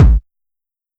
Kicks
DrKick18.wav